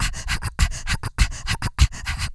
HYPERVENT 2.wav